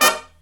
HIGH HIT17-L.wav